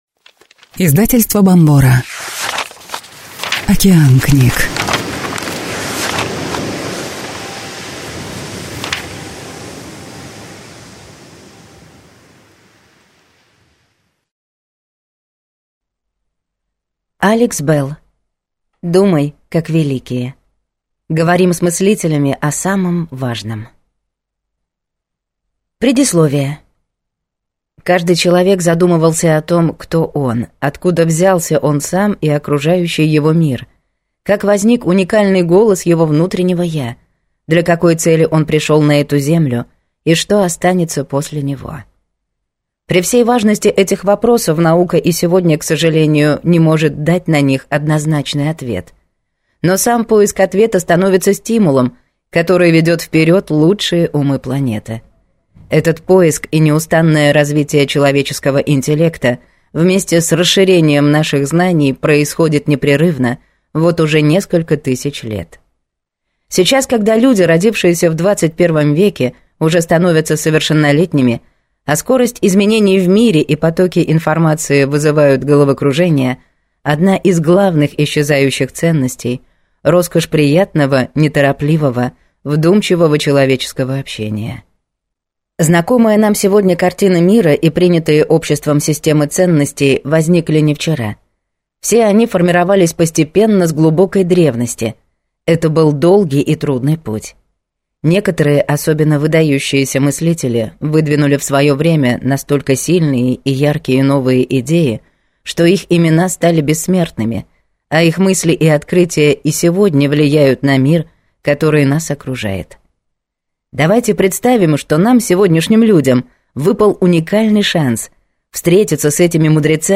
Аудиокнига Думай как великие. Говорим с мыслителями о самом важном | Библиотека аудиокниг